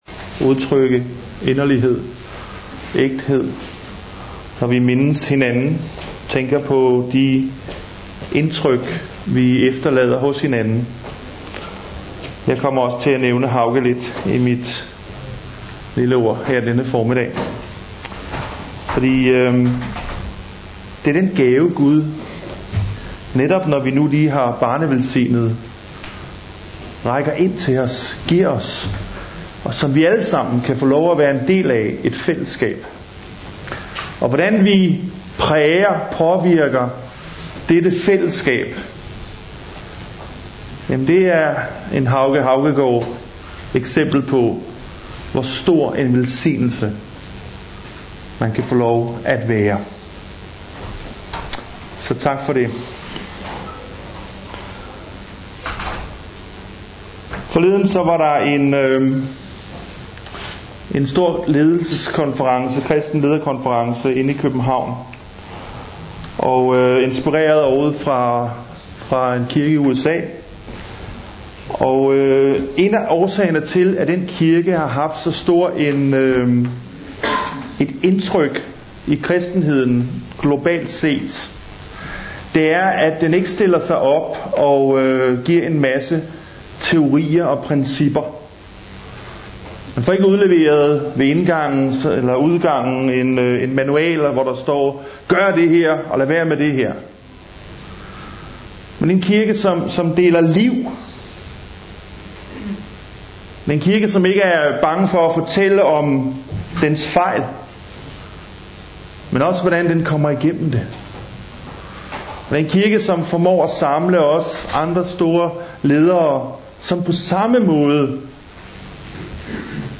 2. november 2014 Type af tale Prædiken Filipperbrevet Bibeltekst Paulus' Brev til Filipperne MP3 Hent til egen PC